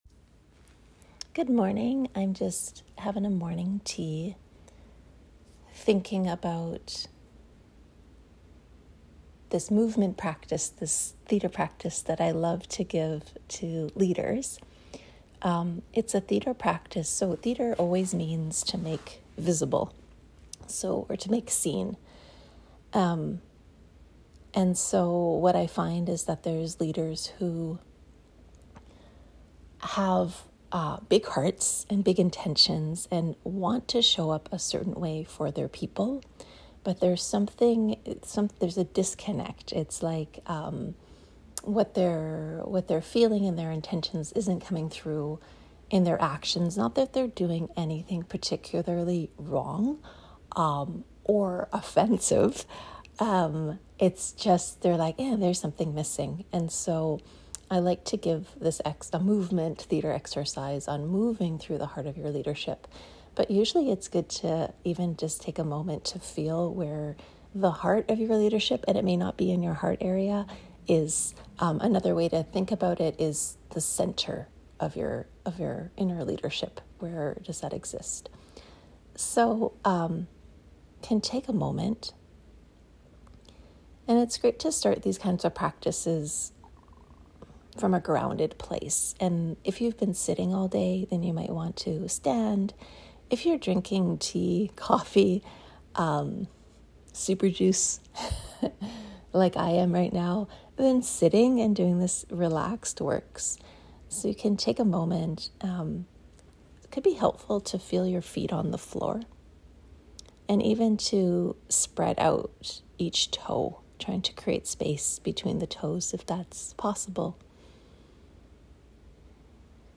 Find Your Leadership Centre Meditation (3.82 MB)